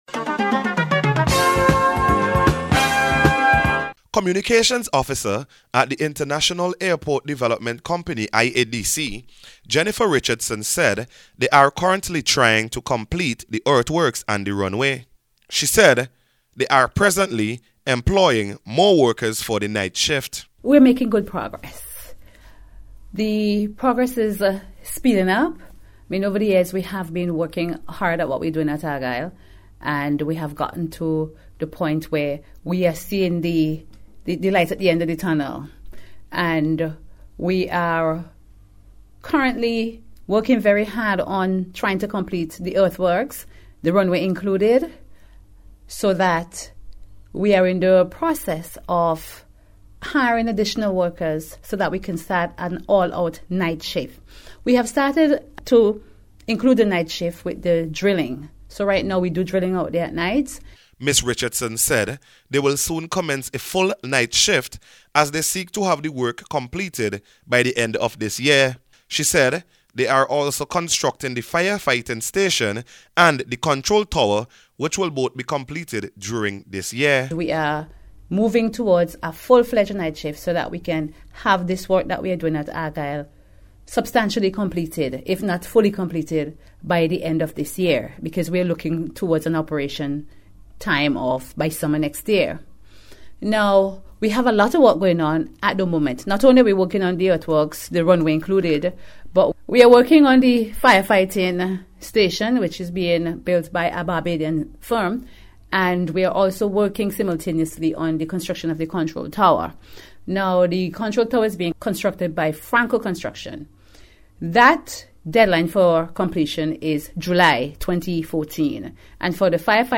AIRPORT-WORK-INCREASE-REPORT.mp3